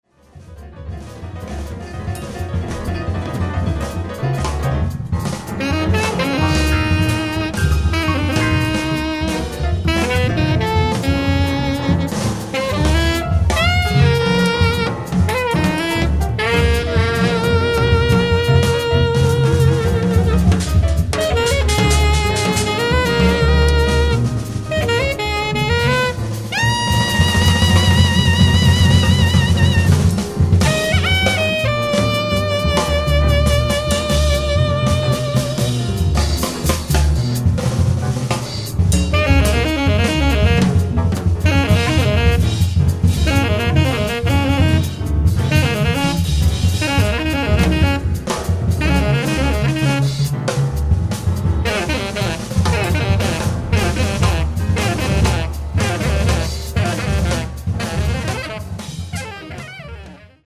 sax contralto, tenore, flauto, clarinetti
pianoforte
batteria, percussioni